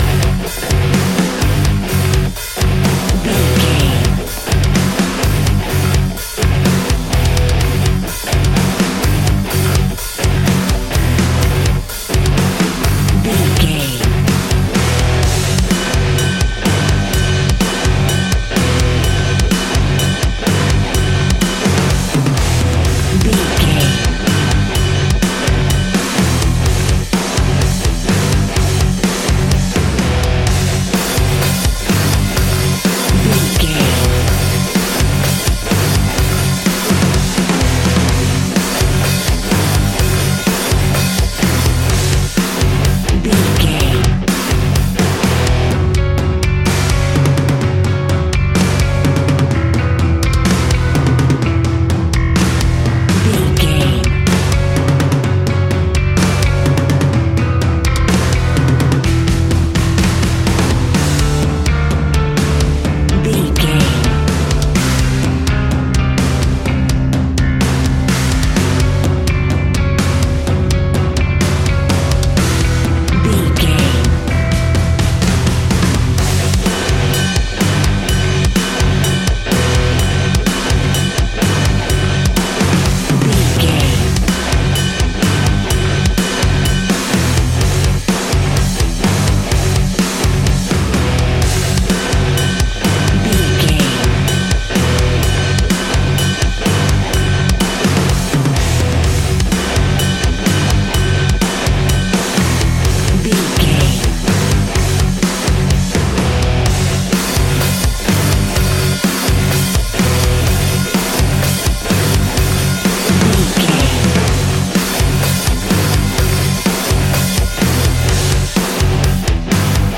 Ionian/Major
F♯
heavy metal
instrumentals